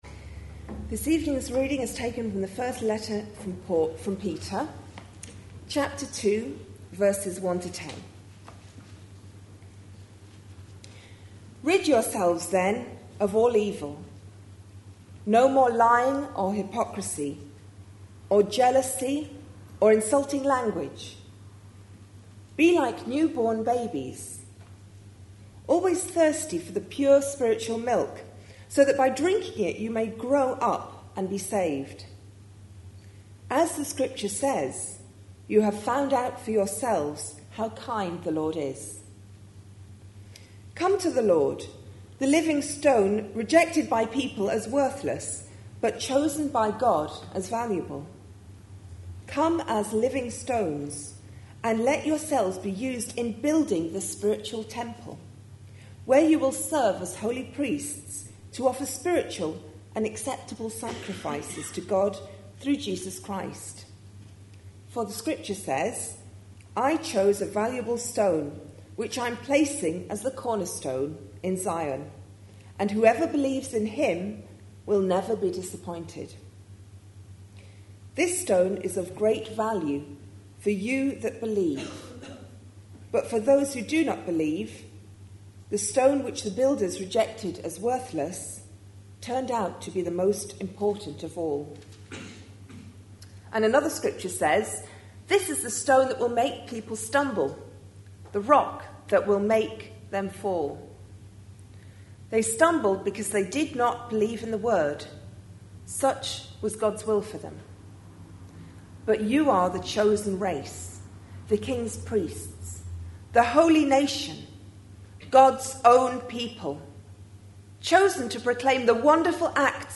A sermon preached on 14th November, 2010, as part of our A Letter to Young Christians series.